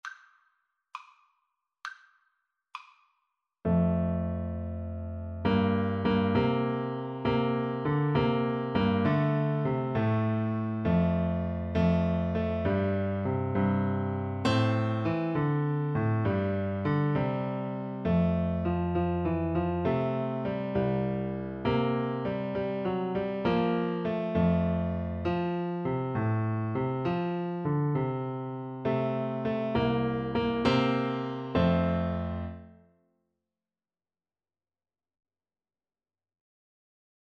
Free Sheet music for Piano Four Hands (Piano Duet)
F major (Sounding Pitch) (View more F major Music for Piano Duet )
6/8 (View more 6/8 Music)
Christmas (View more Christmas Piano Duet Music)